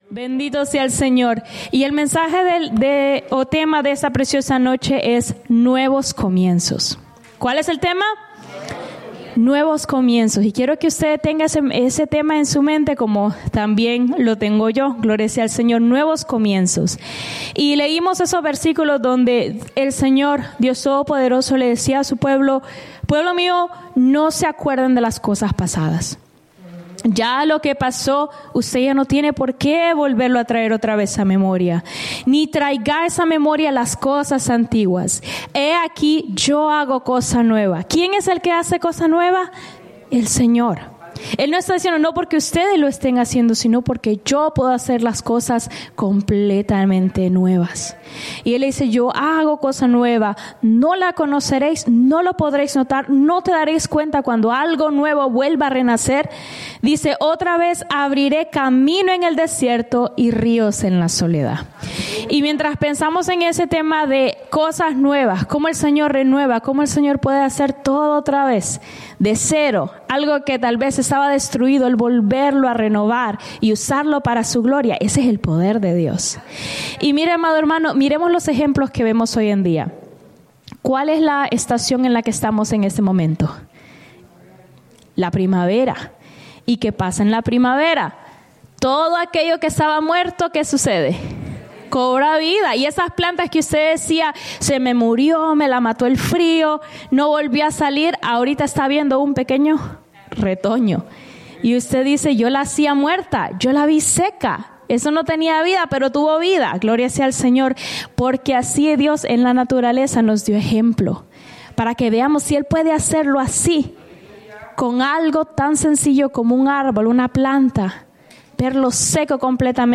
Predica
Souderton, PA